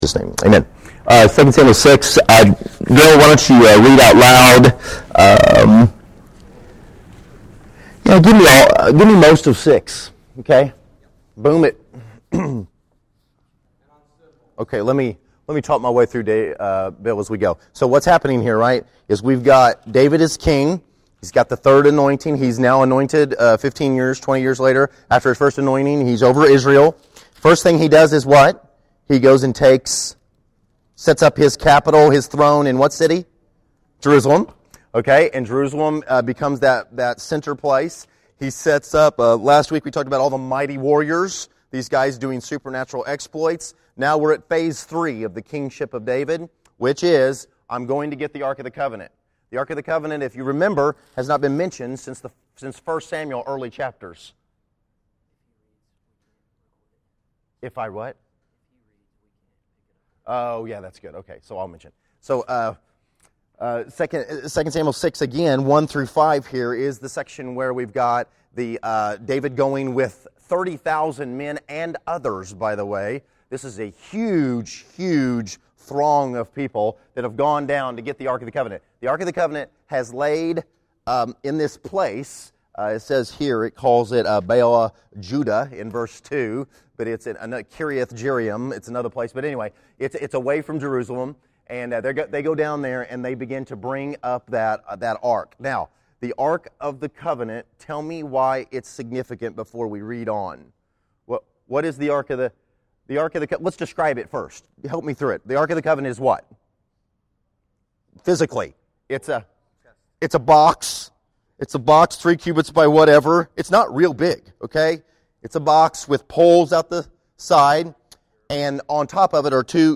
Category: Sunday School | Location: El Dorado Back to the Resource Library Looking at 2 Samuel 6 along with 1 Chronicles 13 & 15: Bringing the ark back to Jerusalem.